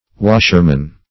Search Result for " washerman" : Wordnet 3.0 NOUN (1) 1. operates industrial washing machine ; [syn: washerman , laundryman ] The Collaborative International Dictionary of English v.0.48: Washerman \Wash"er*man\, n.; pl.